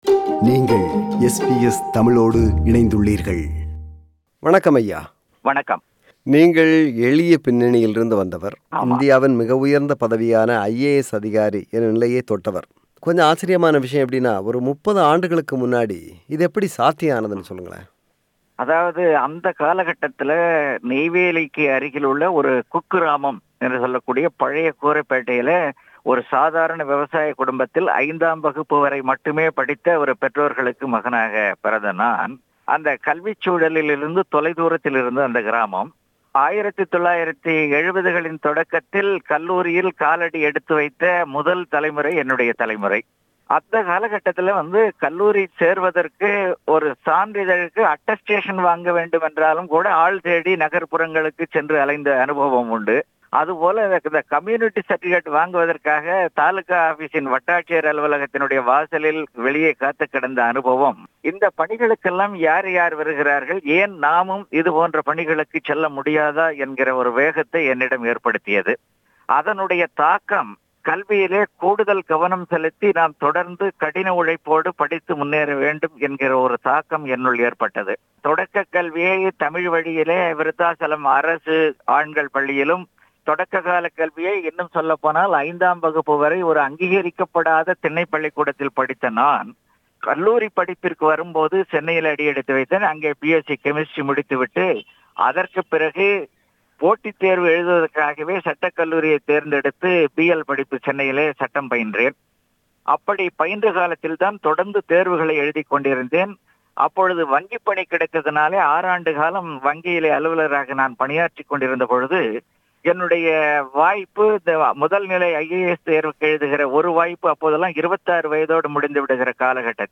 இலக்கியம், நடப்பு அரசியல், தமிழின் நிலை என்று பல்வேறு கேள்விகளுக்கு மனம் திறந்து பதில் தருகிறார் முனைவர் தனவேல் அவர்கள்.